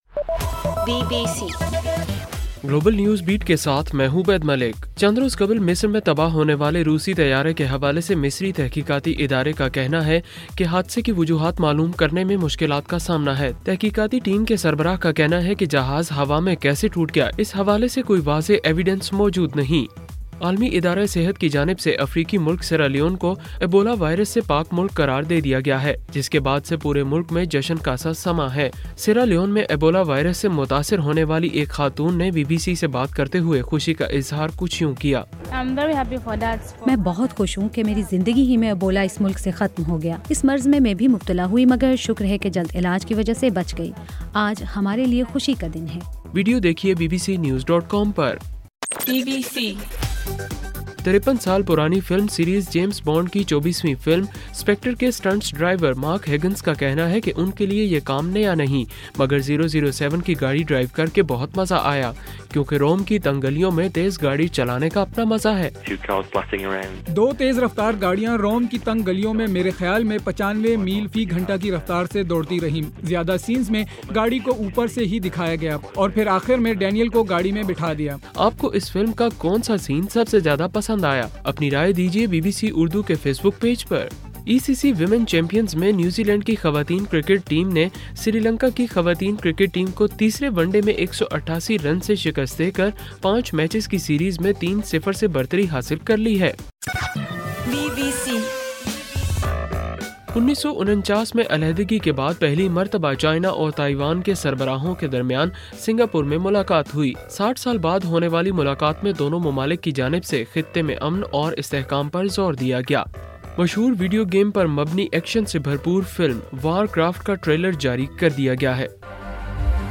نومبر 7: رات 11 بجے کا گلوبل نیوز بیٹ بُلیٹن